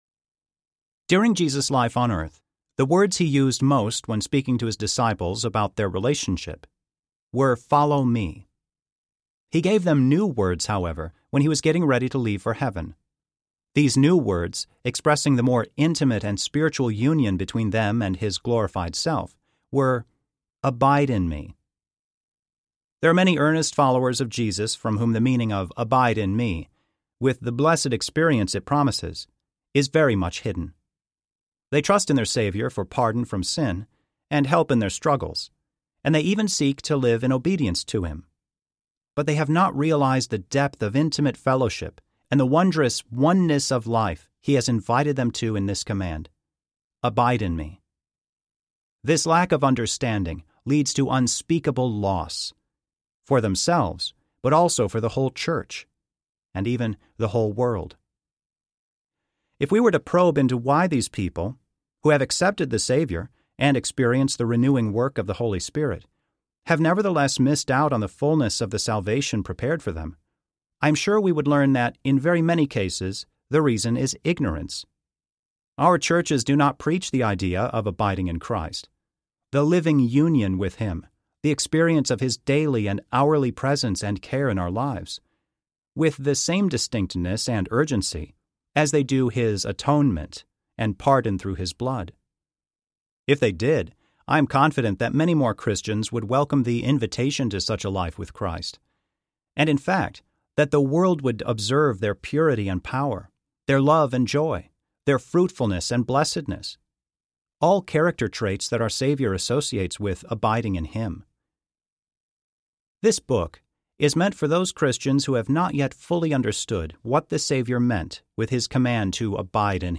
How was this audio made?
5.8 Hrs. – Unabridged